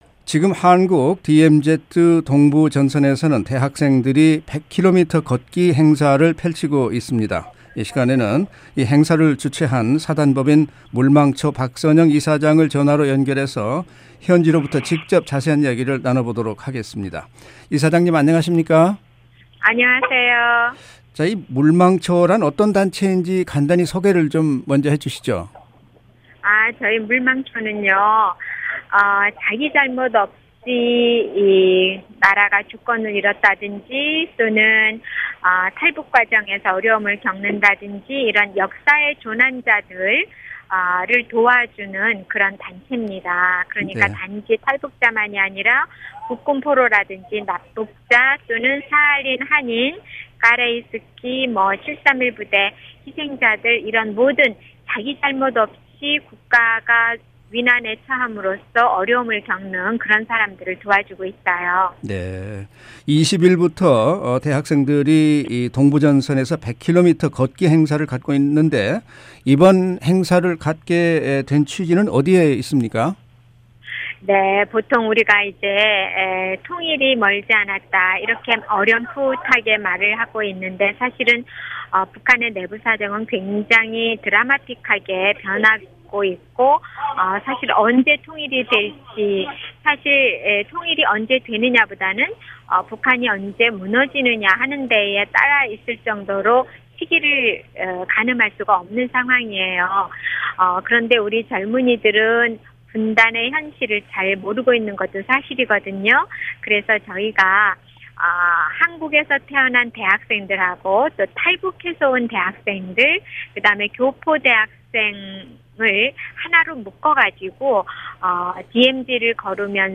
[인터뷰] 사단법인 '물망초' 박선영 이사장
지금 한국 DMZ 동부전선에서는 대학생들의 100km 걷기 행사가 펼쳐지고 있습니다. 한국 학생들은 물론 탈북 학생, 해외에서 온 교포 학생들도 참여하고 있는데요. 행사를 주최한 사단법인 ‘물망초’ 박선영 이사장을 전화로 연결해 자세한 이야기 나눠보겠습니다.